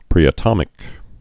(prēə-tŏmĭk)